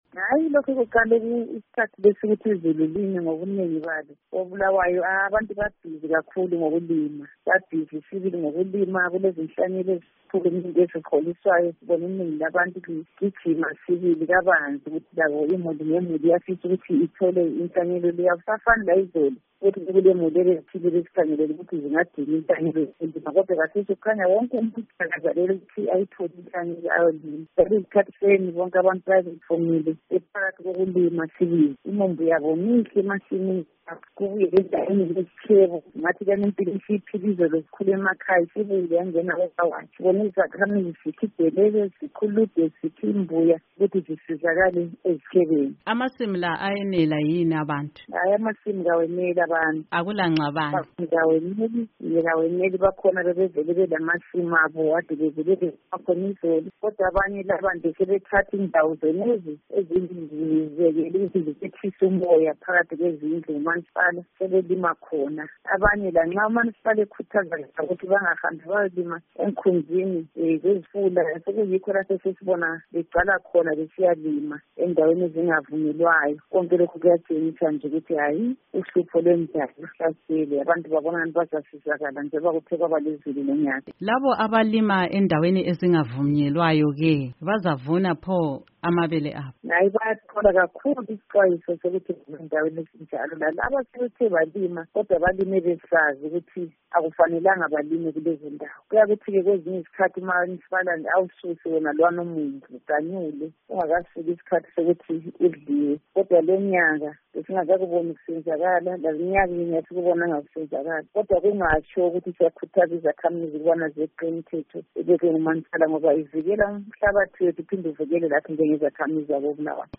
Ukuze sizwe okwenzakala koBulawayo ngaloludaba, sixoxe loKhansila Ntombizodwa Khumalo kaWard 23 eNkulumane.
Ingxoxo loKhansila Ntombizodwa Khumalo